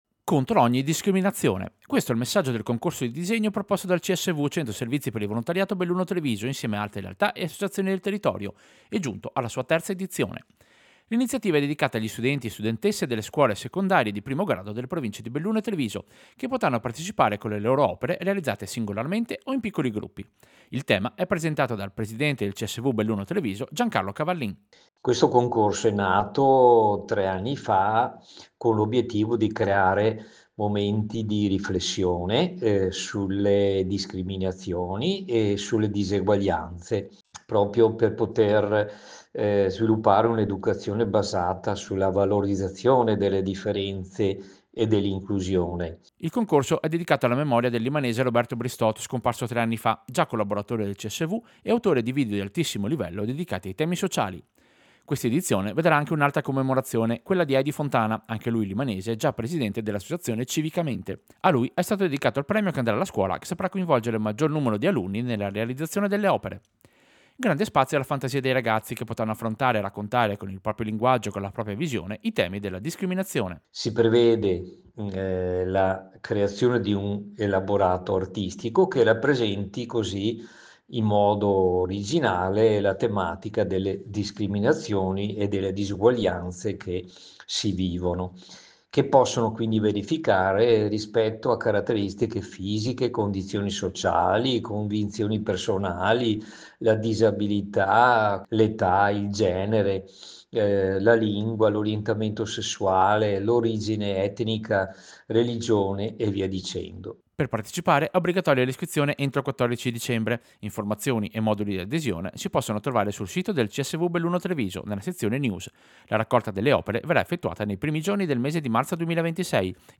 Servizio-Concorso-disegno-discriminazione-CSV.mp3